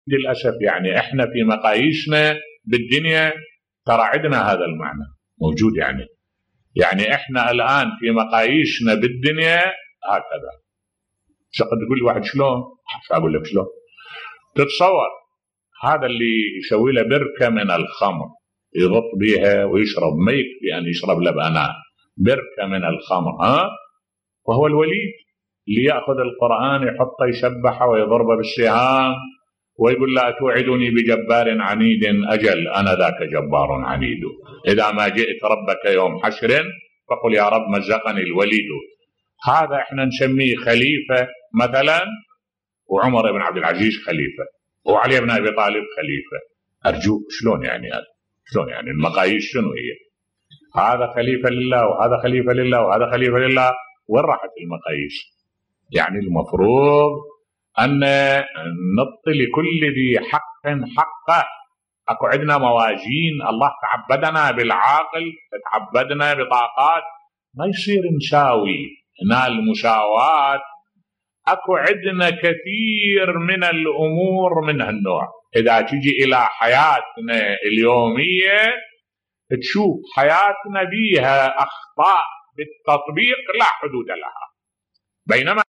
ملف صوتی خليفة المسلمين يمزق القرآن بالسهام بصوت الشيخ الدكتور أحمد الوائلي